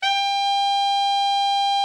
TENOR 36.wav